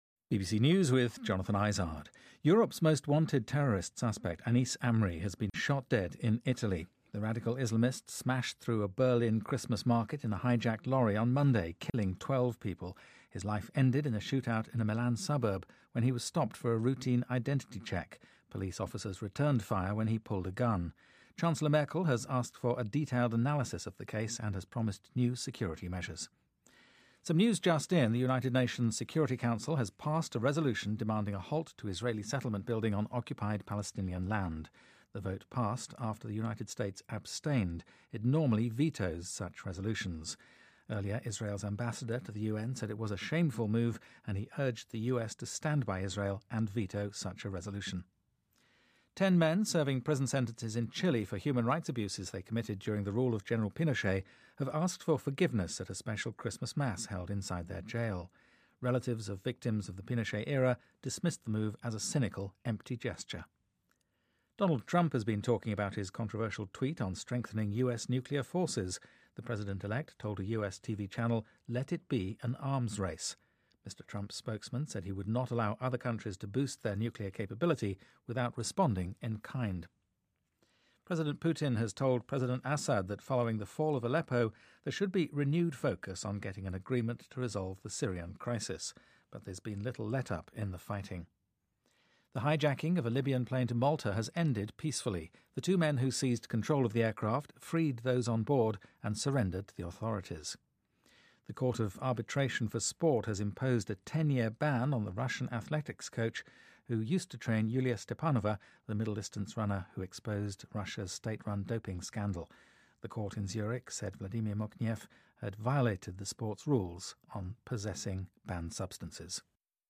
BBC news,欧洲一号通缉犯阿米尔在意大利被击毙